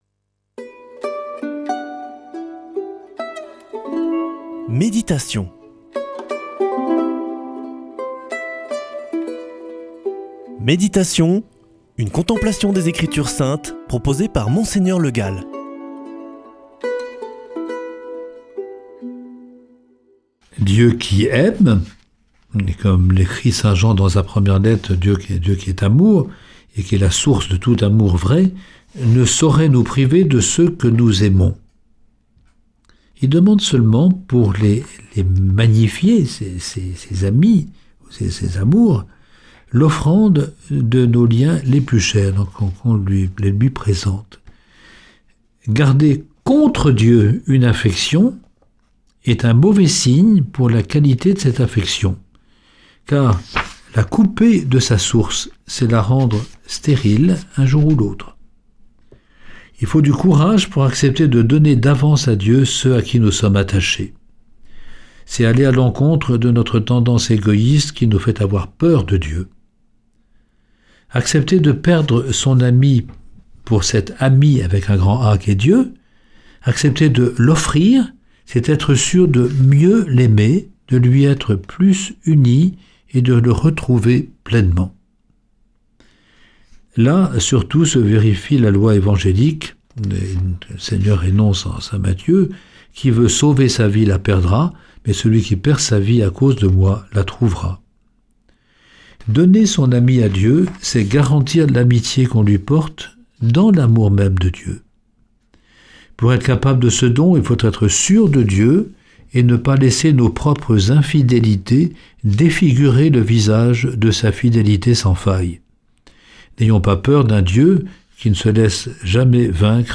Méditation avec Mgr Le Gall
[ Rediffusion ] L’admiration de Dieu pour son ami